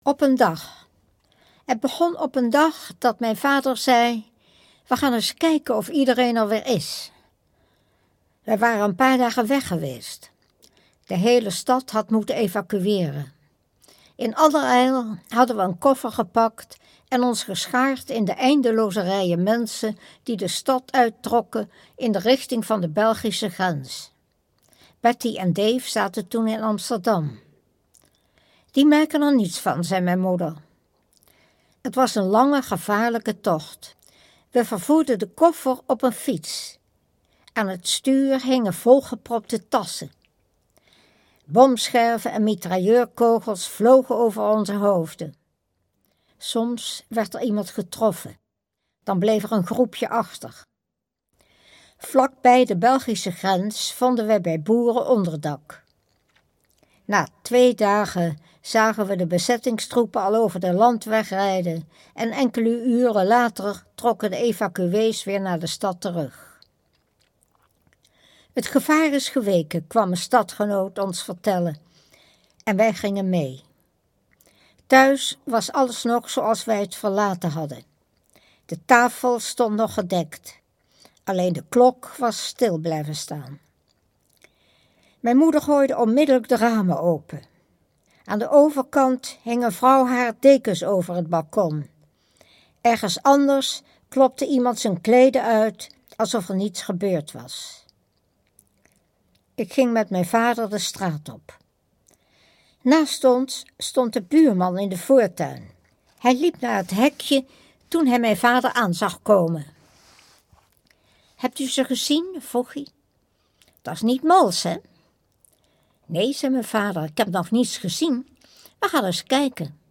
Marga Minco leest Het bittere kruid